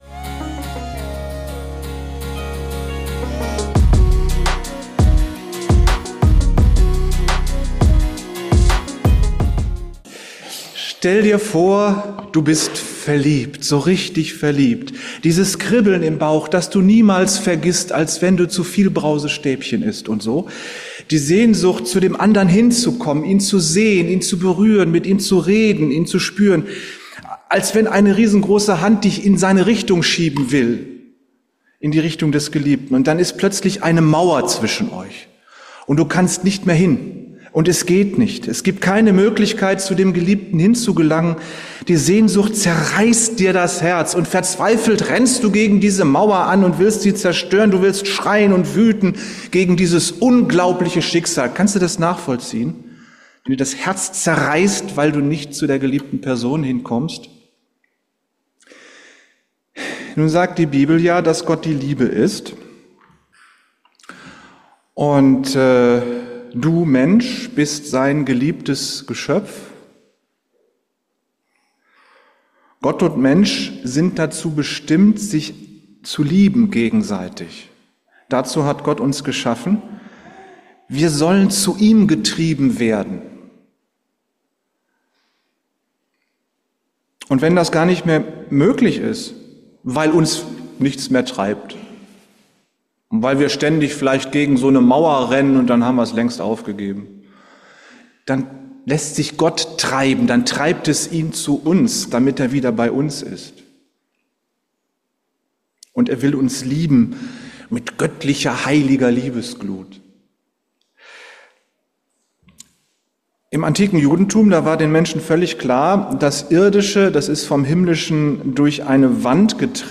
7.7.2024: Die Freie evangelische Gemeinde Wuppertal-Barmen lernt, dass die Versammlung der Menschen, die an Christus glauben das "Allerheiligste" des Gottestempels ist. Durch das Blut von Jesus Christus sind sie 'gereinigt' und dürfen Gott persönlich begegnen.